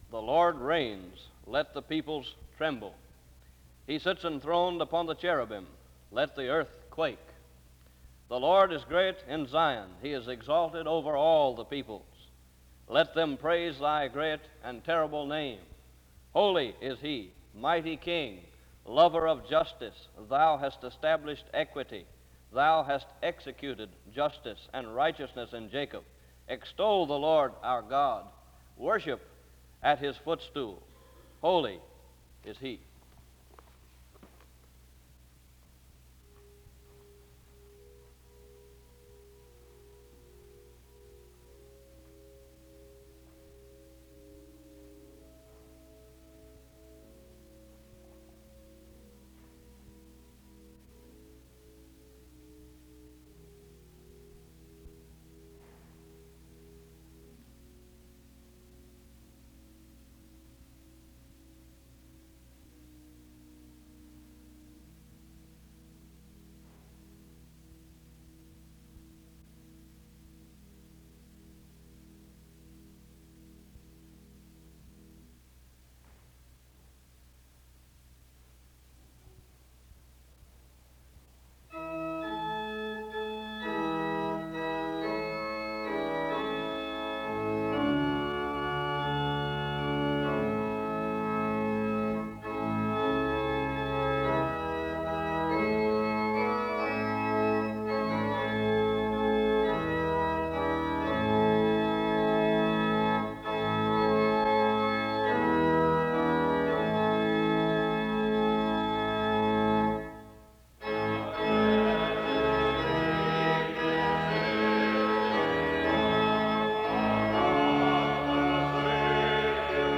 The service opens with a scripture reading from 0:00-0:34. Music plays from 0:45-4:13. A responsive reading takes place from 4:23-5:52. An introduction to the speaker is given from 5:53-6:39.